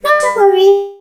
nani_die_vo_04.ogg